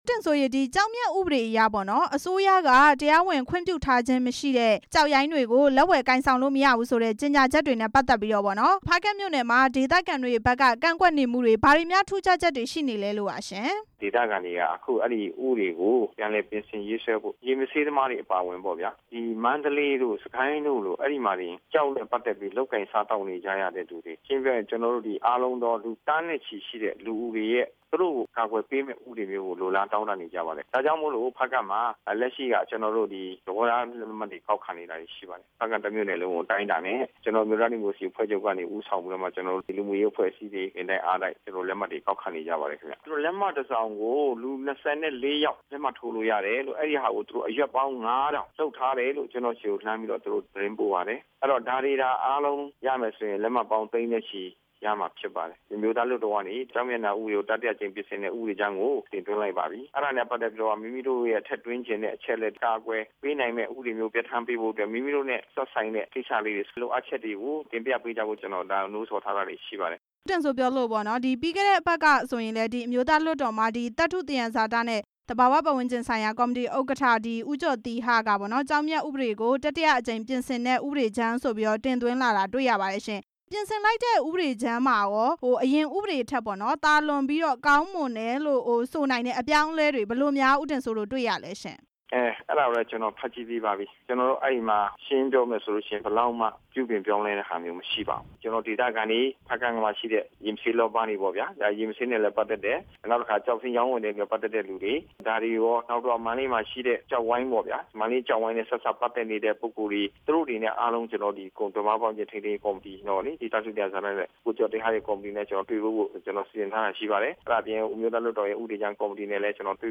ဖားကန့်မြို့နယ် ပြည်သူ့လွှတ်တော်ကိုယ်စားလှယ် ဦးတင်စိုးနဲ့ မေးမြန်းချက်